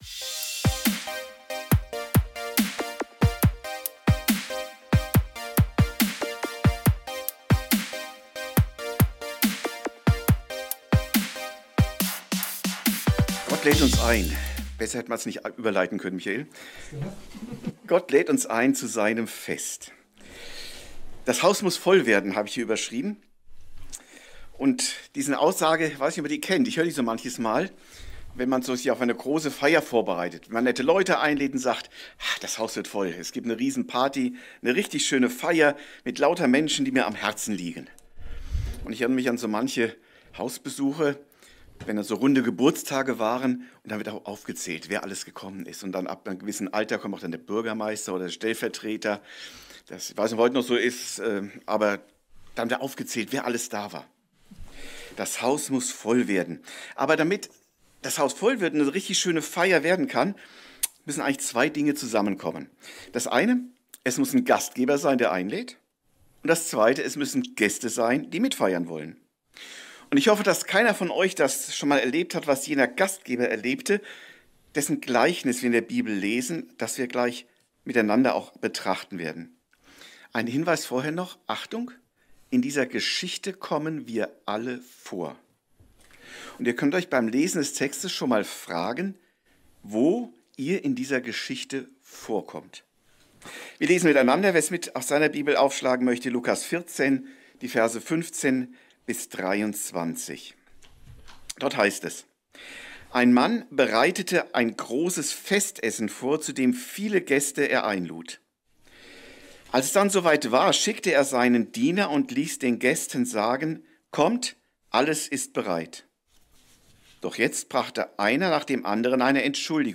Party forever - Das Haus muss voll werden! ~ Predigten u. Andachten (Live und Studioaufnahmen ERF) Podcast